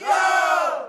congraduate_sound.mp3